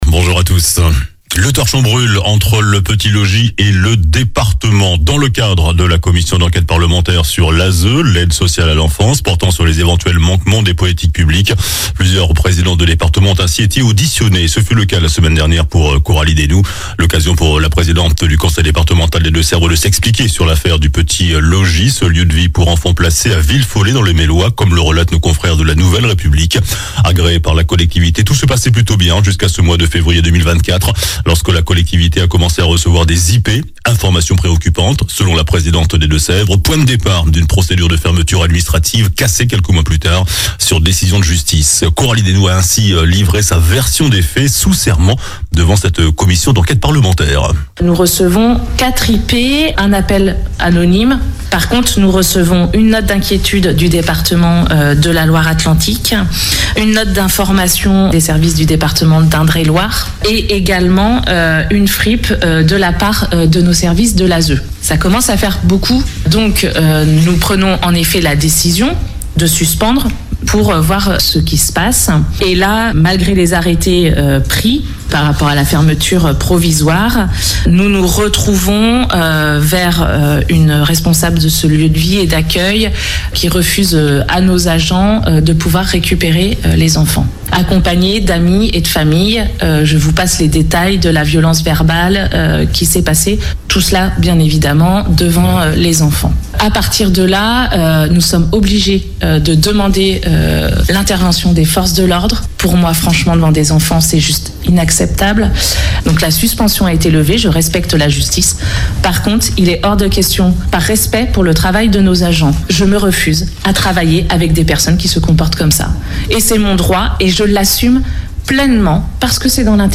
JOURNAL DU SAMEDI 01 FEVRIER